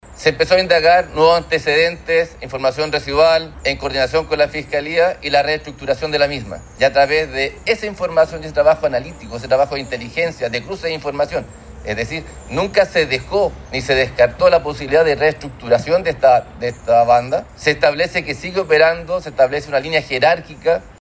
El director general de la Policía de Investigaciones, Eduardo Cerna, informó que el origen de este operativo es de 2024 y se denominó el Tren del Mar, correspondiente a una célula del Tren de Aragua que operaba en la zona céntrica de la Ciudad Jardín.